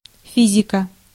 Ääntäminen
IPA: [fi.zik]